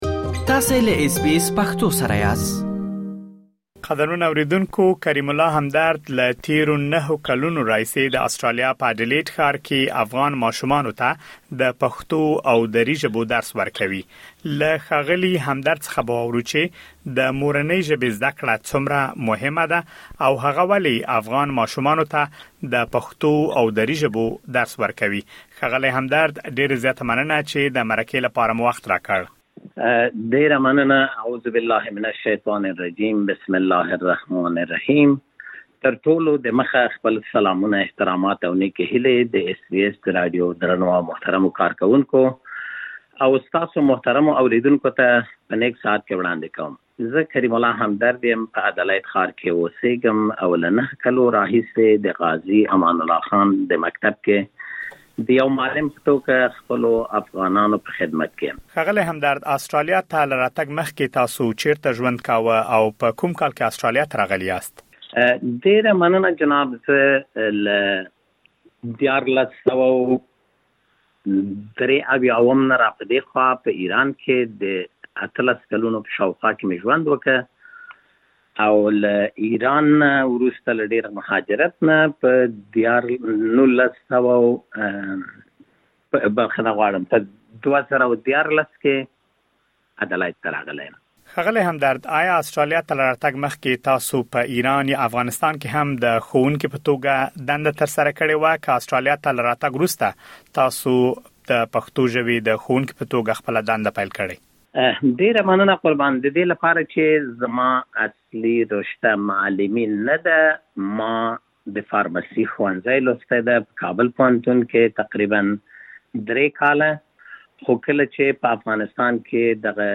په ترسره شوې مرکې کې اورېدلی شئ.